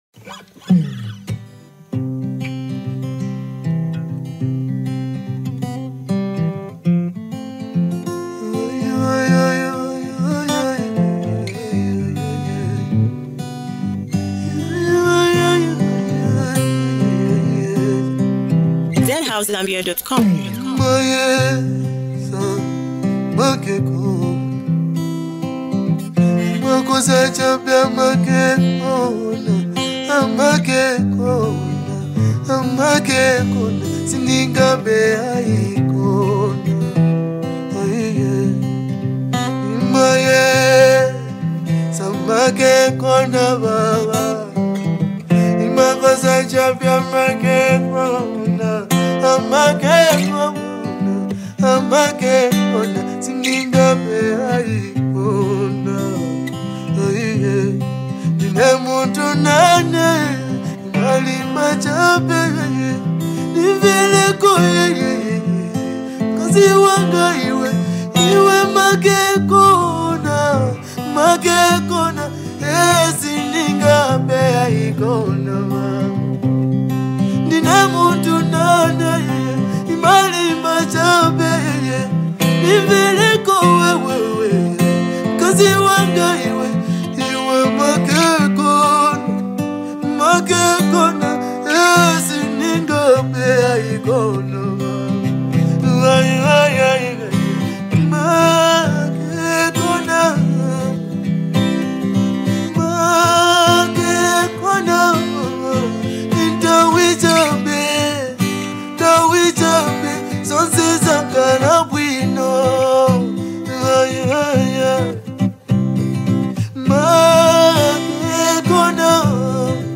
soulful vocals and heartfelt lyrics
accompanied by soothing guitar melodies.